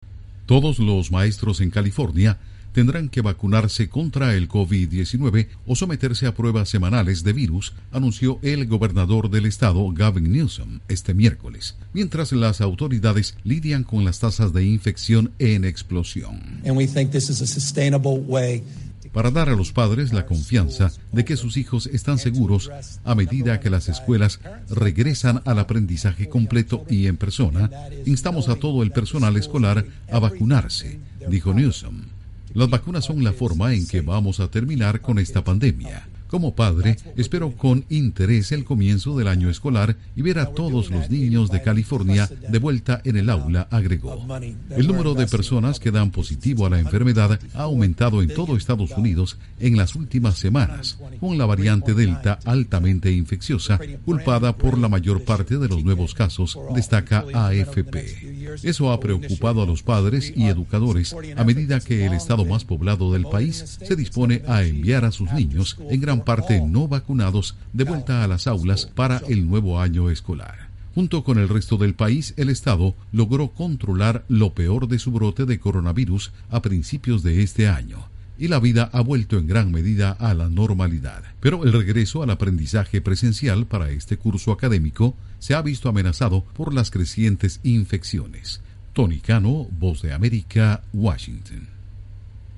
California exige vacunas contra el Covid para todos los maestros. Informa desde la Voz de América en Washington